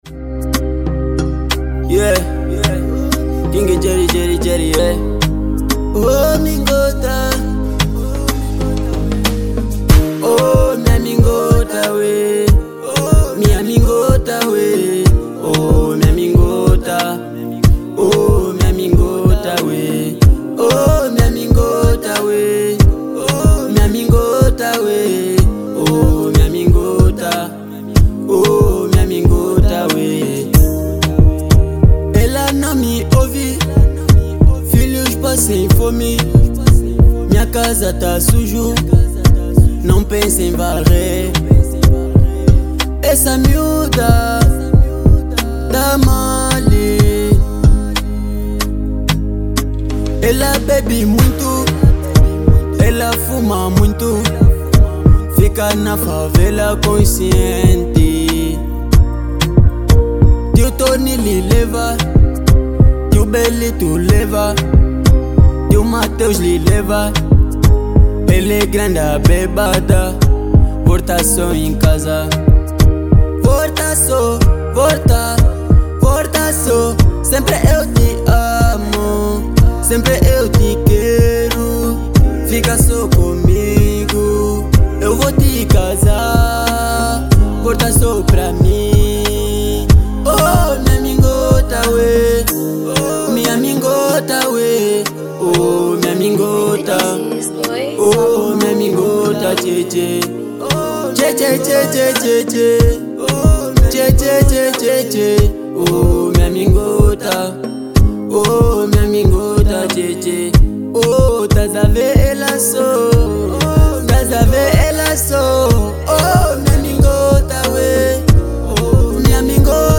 | Zouk